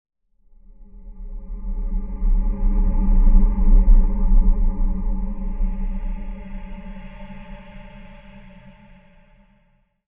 z_horror.wav